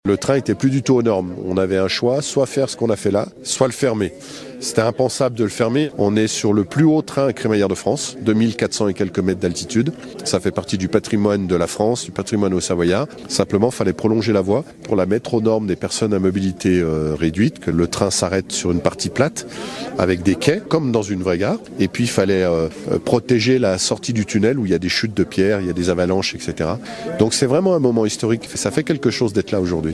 Martial Saddier est le président du Département.